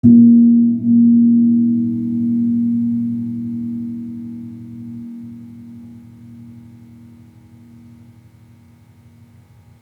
Gong-A#2-p.wav